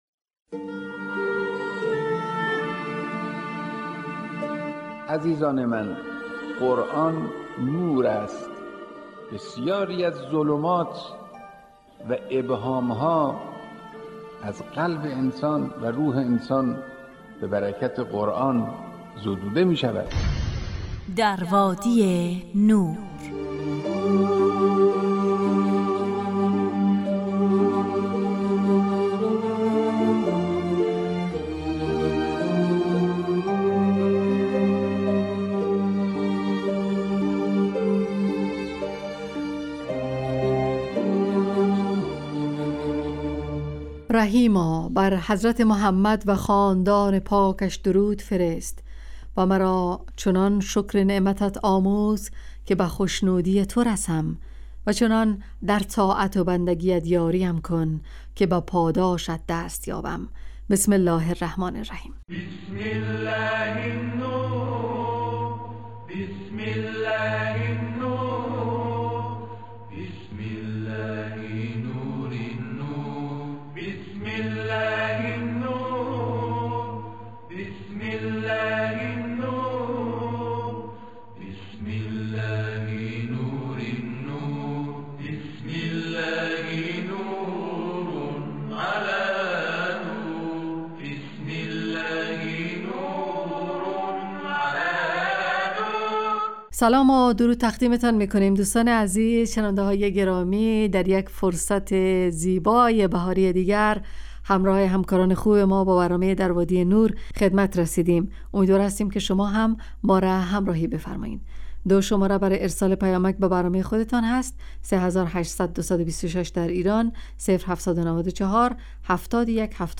در وادی نور برنامه ای 45 دقیقه ای با موضوعات قرآنی روزهای فرد: ( قرآن و عترت،طلایه داران تلاوت ، دانستنیهای قرآنی، ایستگاه تلاوت، تفسیر روان و آموزه های...